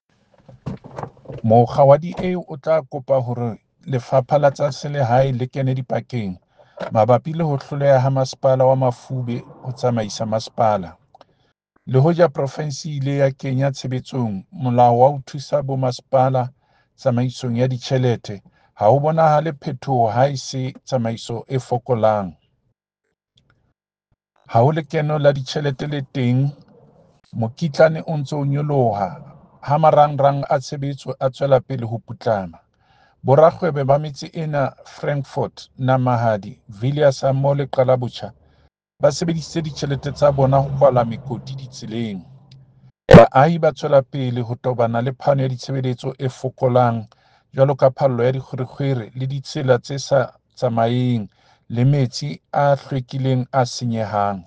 Sesotho soundbite by David Masoeu MPL.